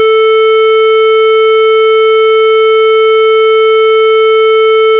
CHOMPStation2/sound/instruments/synthesis_samples/tones/Square.ogg at bb2be4cf3332ebab11c76c901b963362e754cf5b
Square.ogg